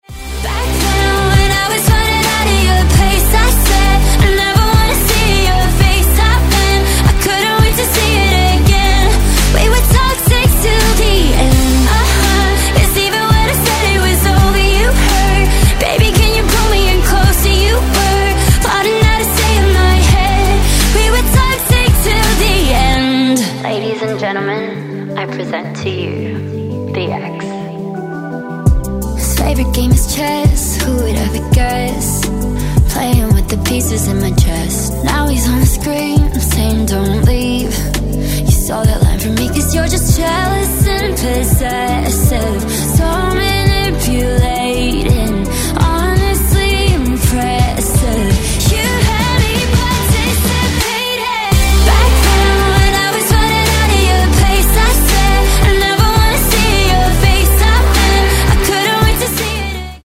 K-Pop File format